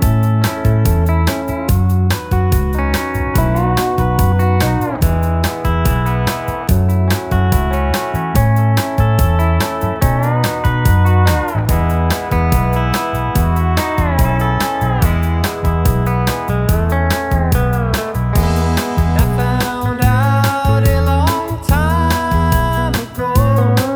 Minus Bass Guitar Soft Rock 4:15 Buy £1.50